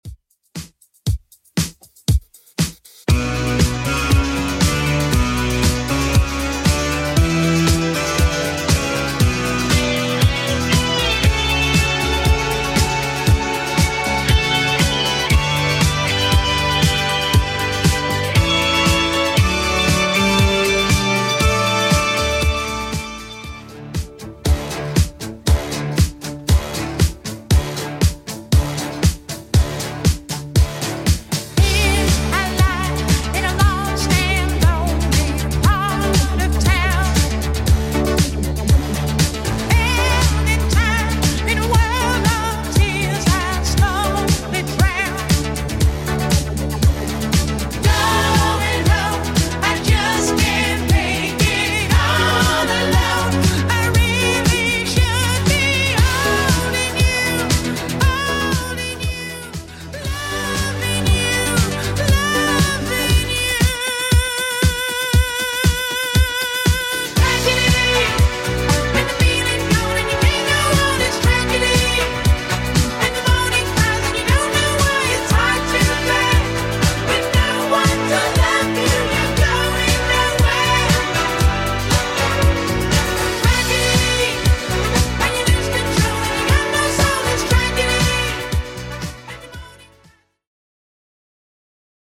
Genre: 70's
BPM: 115